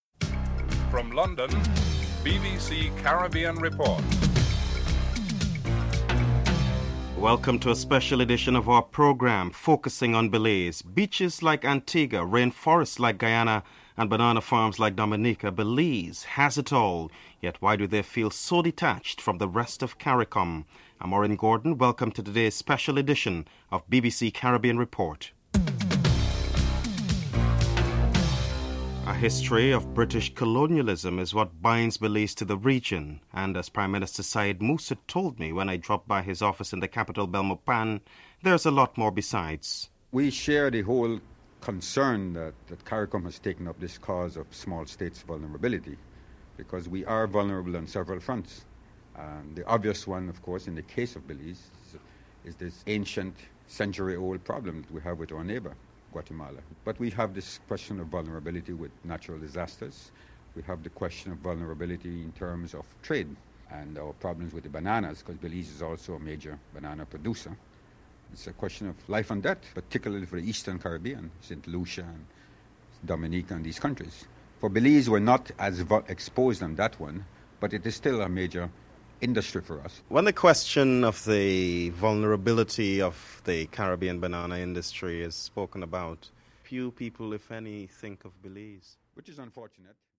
1. Headlines: (00:00-00:28)